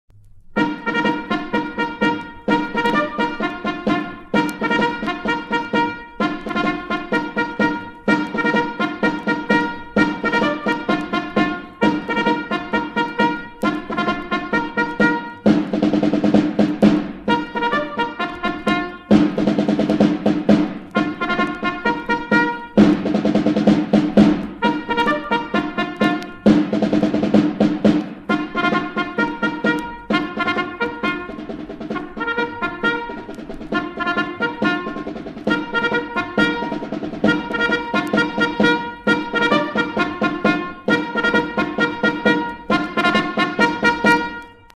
5 станция. Символика пионерии – Горн, барабан.
Давайте послушаем примеры звучания сигналов горна и барабана.
Демонстрация сигналов подъема и отбоя,
сбор на линейку и пионерский марш.
pionerskiy_gorn_i_baraban.mp3